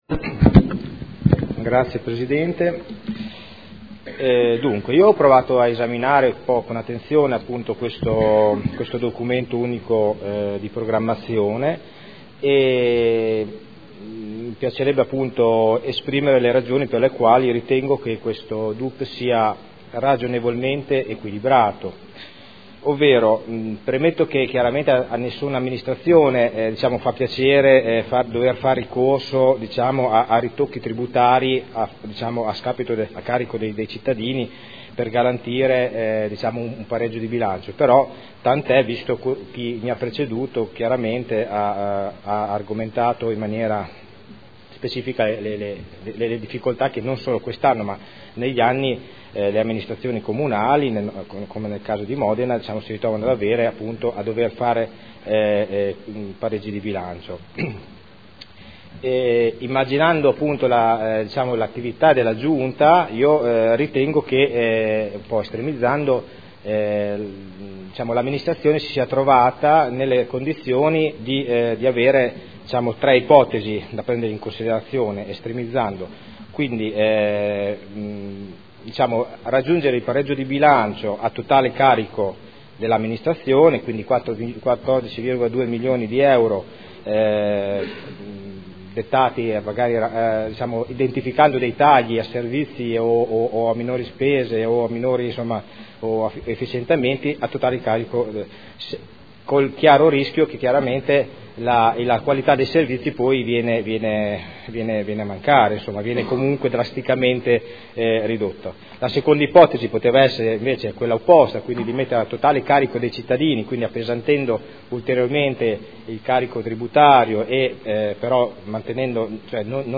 Seduta del 29/01/2015. Documento Unico di Programmazione 2015/2019 – Sezione strategica. Dibattito